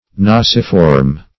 Search Result for " nasiform" : The Collaborative International Dictionary of English v.0.48: Nasiform \Nas"i*form\ (n[a^]z"[i^]*f[^o]m), a. [L. nasus nose + -form.